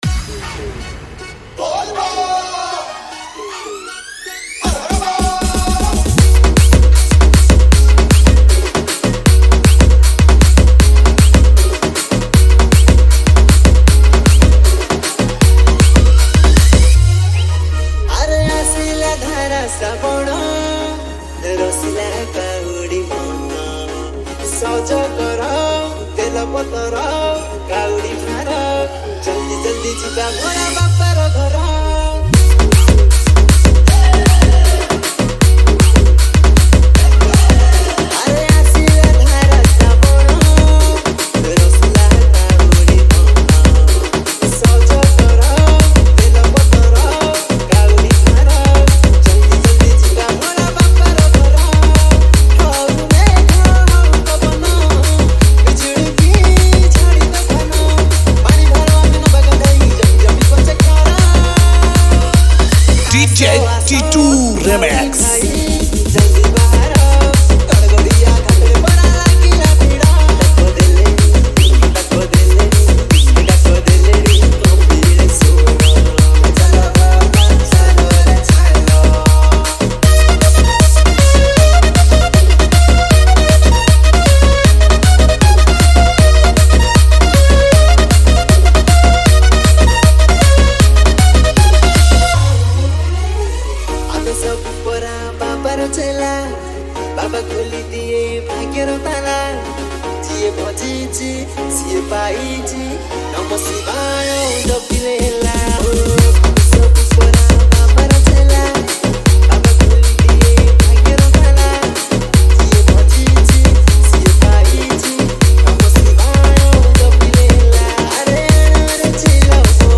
Category:  Odia Bhajan Dj 2025